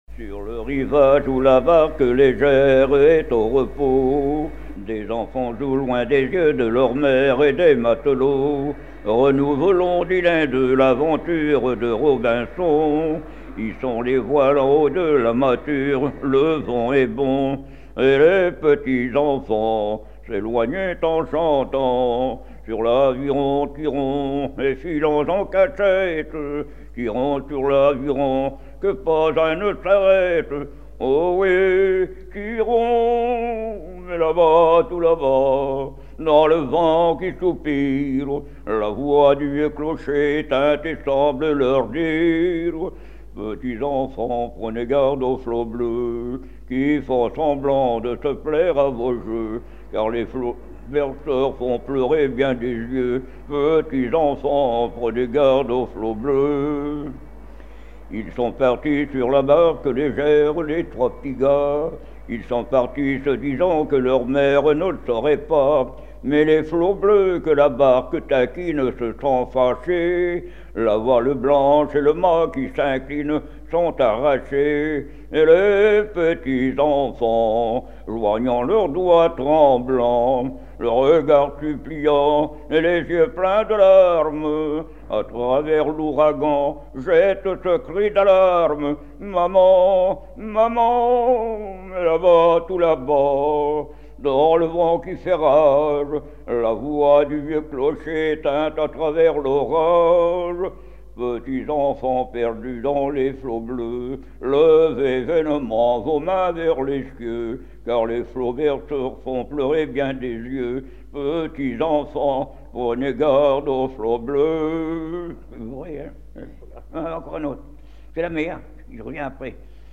Genre strophique
répertoire de chansons
Pièce musicale inédite